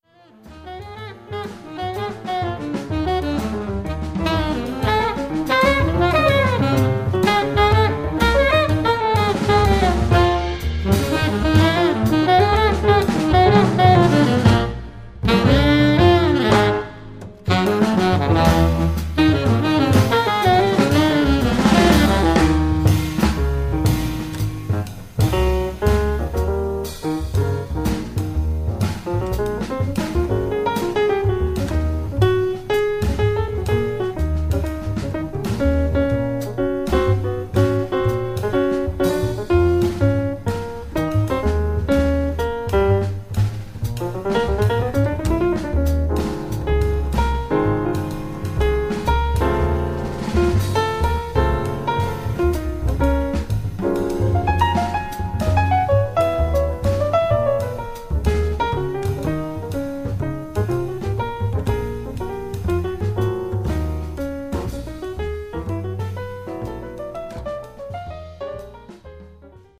piano
alto & soprano sax
bass
drums